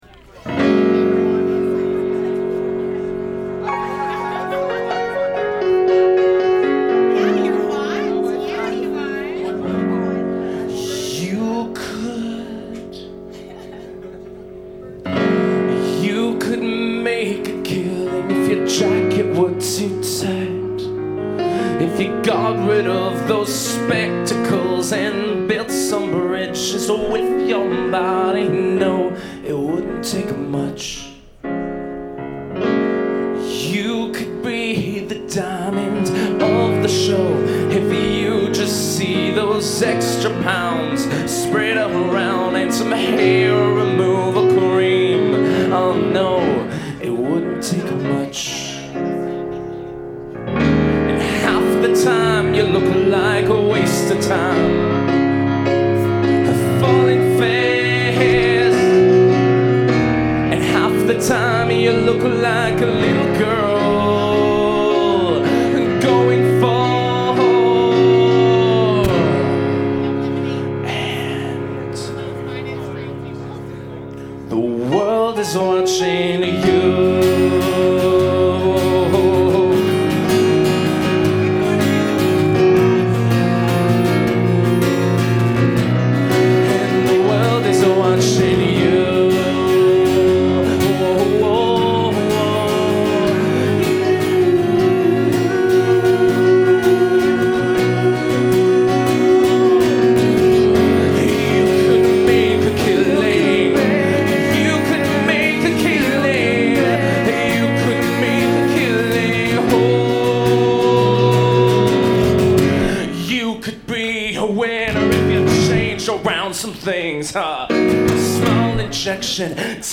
(live)
soundboard recording